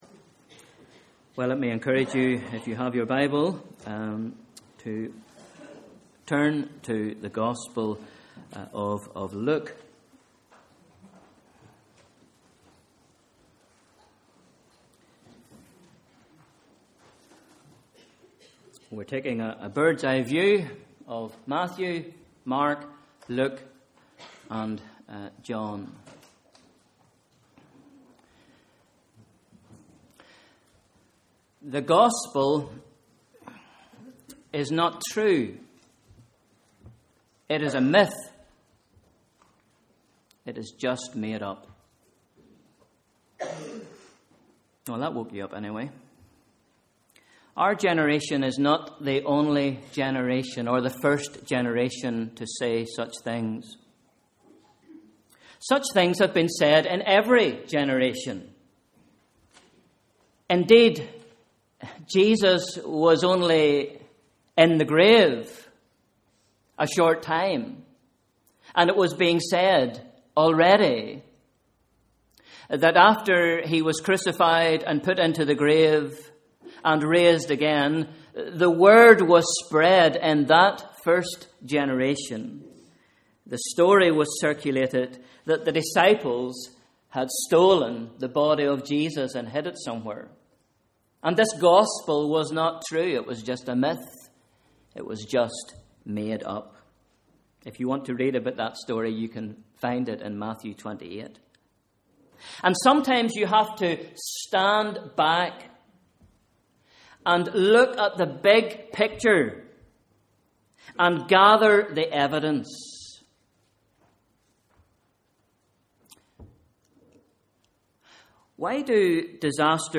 Morning Service: sunday 24th November 2013 Bible Reading: Luke 1 v 1-4